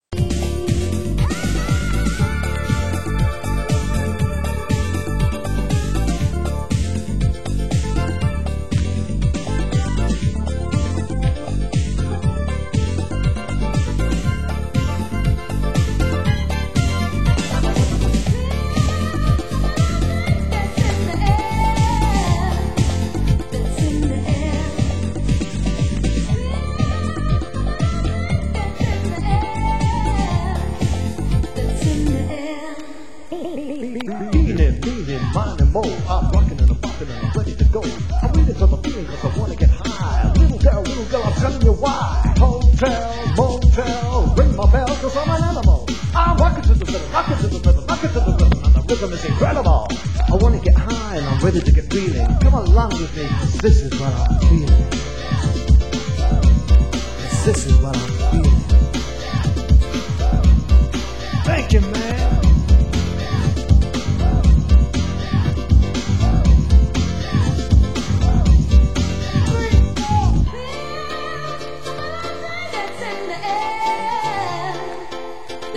HomeElectro / New grooves  >  Deep House